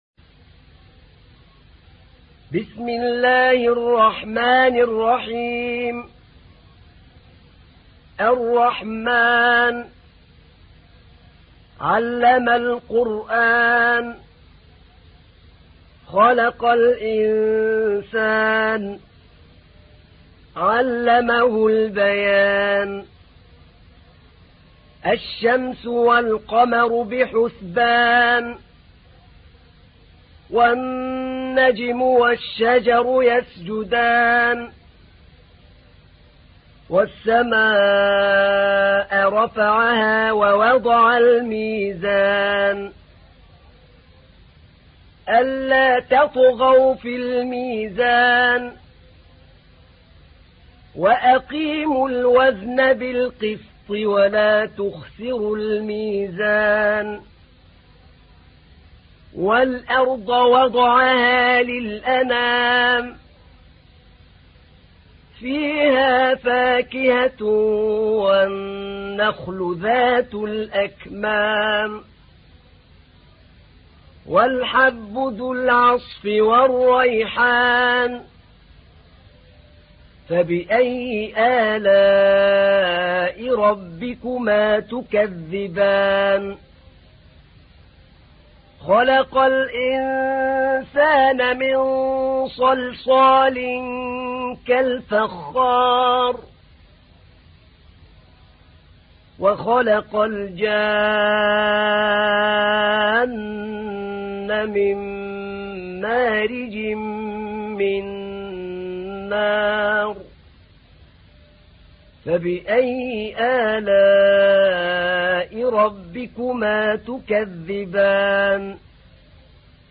تحميل : 55. سورة الرحمن / القارئ أحمد نعينع / القرآن الكريم / موقع يا حسين